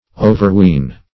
Search Result for " overween" : The Collaborative International Dictionary of English v.0.48: Overween \O`ver*ween"\, v. t. [AS. oferw[=e]nian.
overween.mp3